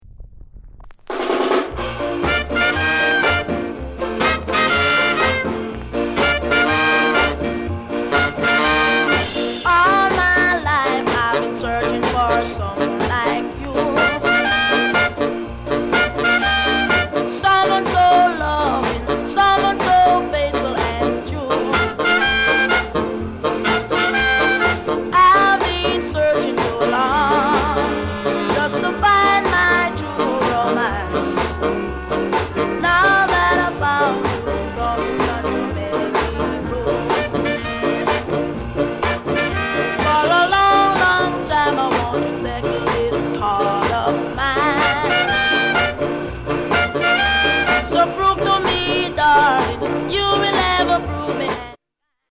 AUTHENTIC SKA